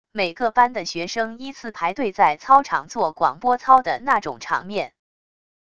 每个班的学生依次排队在操场做广播操的那种场面wav音频